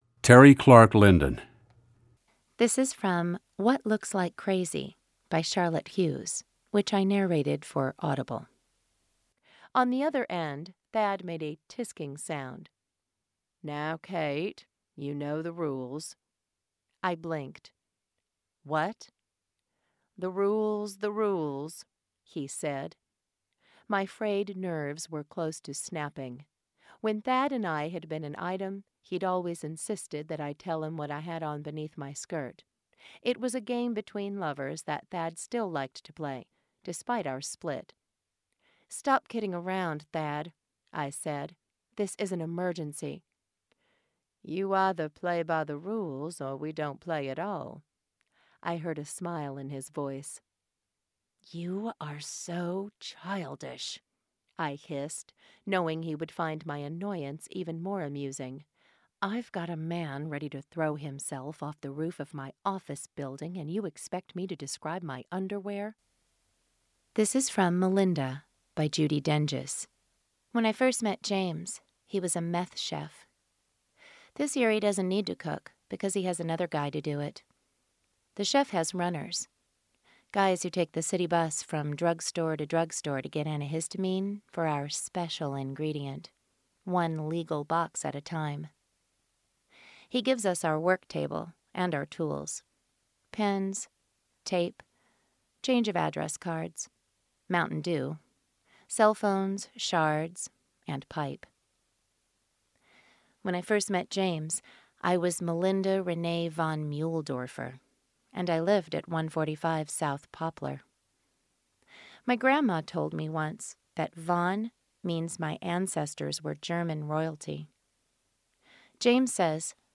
voiceover : audio books